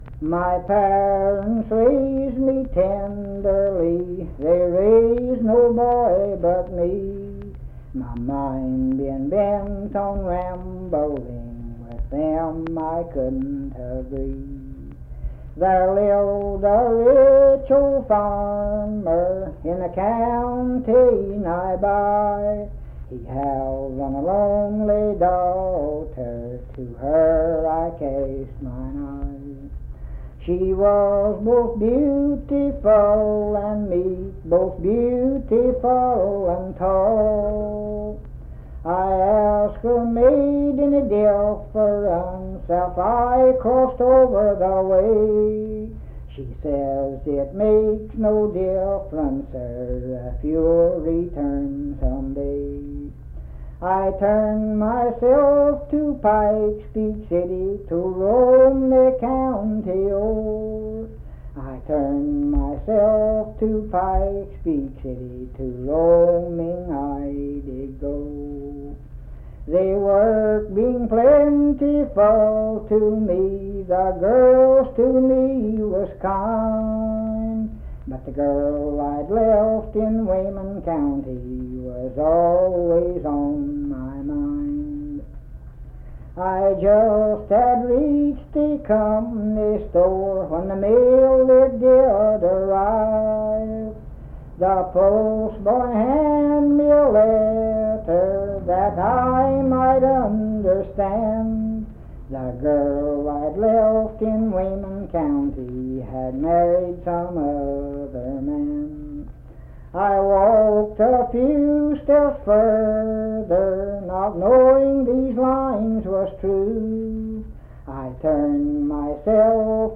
Unaccompanied vocal music
Laws P1A-B. Performed in Naoma, Raleigh County, WV.
Voice (sung)